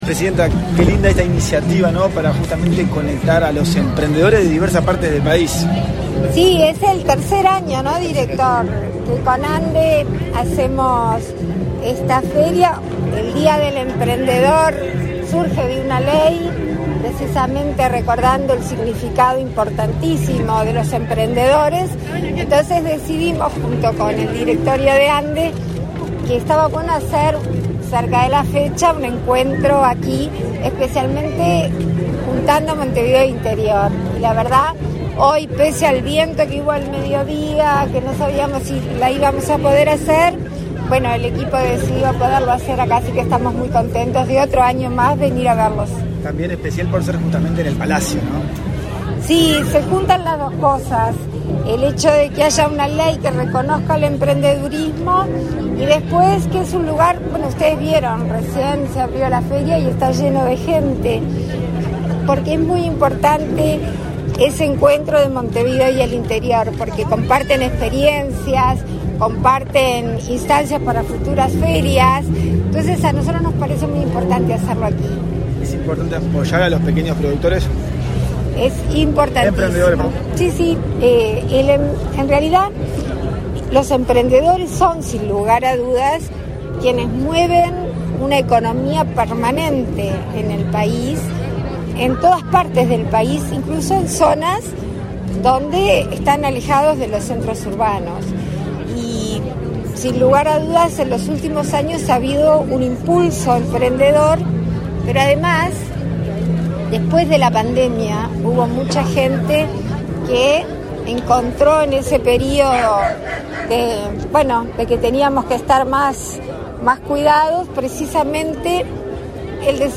Acto de apertura de la Feria Emprendedora
En la disertación, además de Argimón, participó la presidenta de la ANDE, Carmen Sánchez.